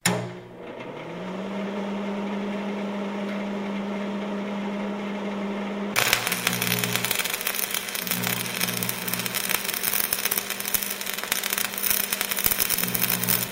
Звуки сварки
• Качество: высокое
На этой странице собрана коллекция реалистичных звуков сварочных работ.